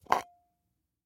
Звук: баллончик сняли с подставки